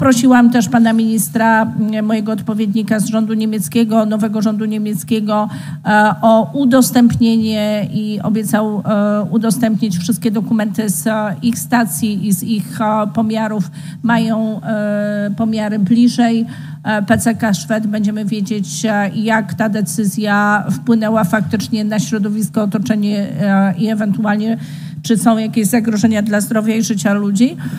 Odpowiednik rządu niemieckiego – jak minister środowiska w Polsce – ma przygotować dane odnośnie tego jak rafineria w Schwedt wpływa na otoczenie – powiedziała podczas sejmiku minister środowiska Paulina Henning – Kloska